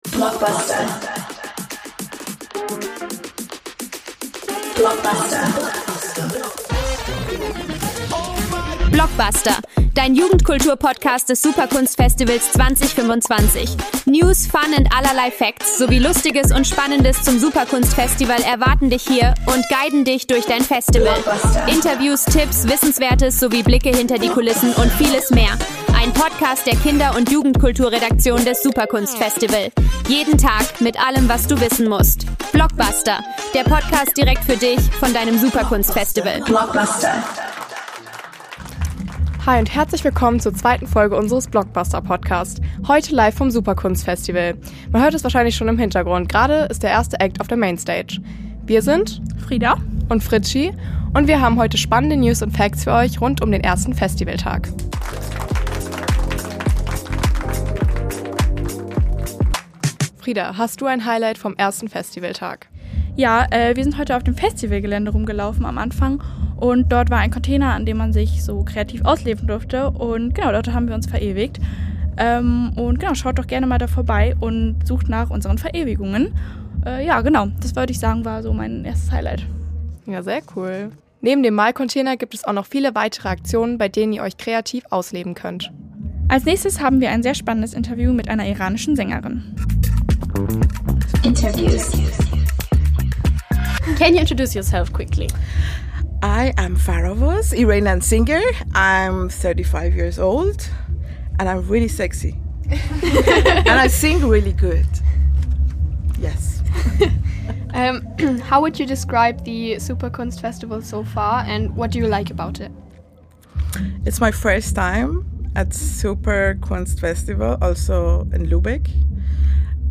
Interview
Seid gespannt auf einer Folge voller Energie, Blicke hinter die Kulissen und einer gehörigen Ladung Festivalstimmung.